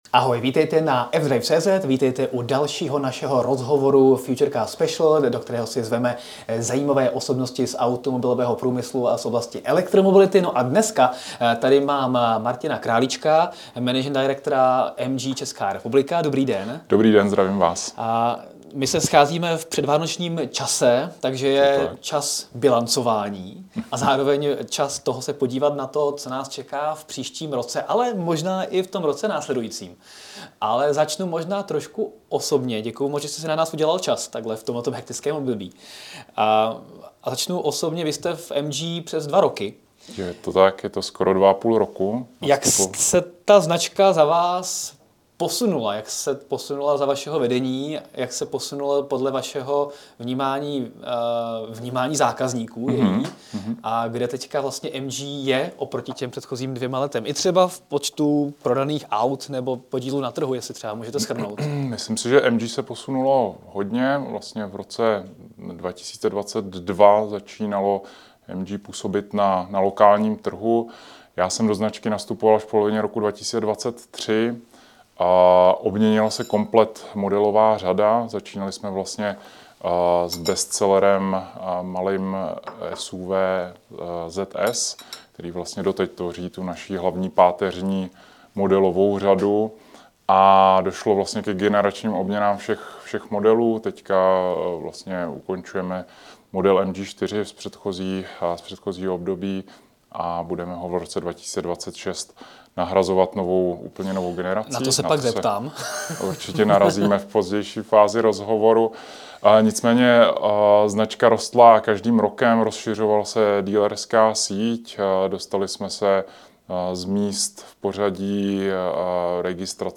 Rozhovor